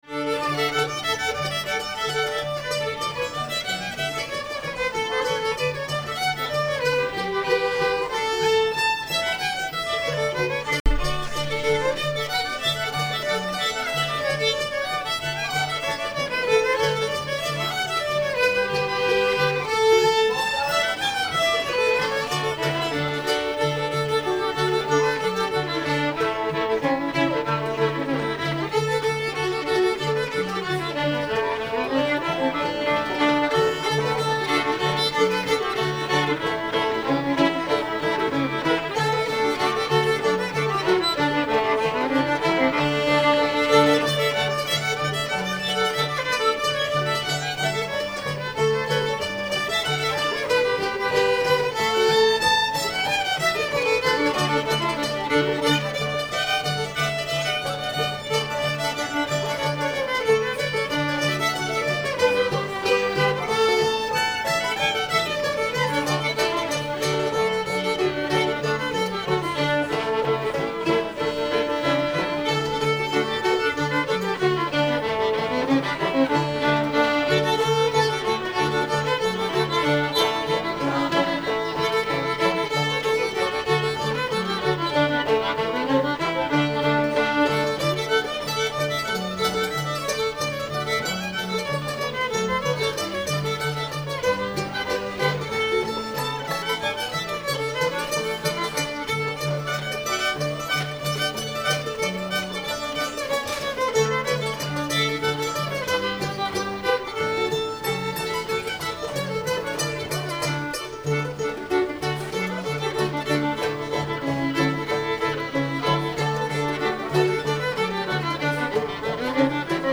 [G]